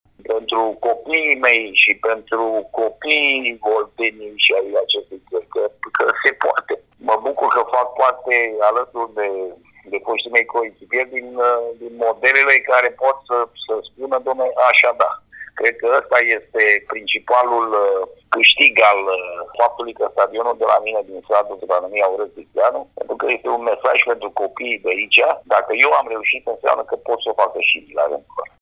Într-un interviu pentru Radio România Oltenia Craiova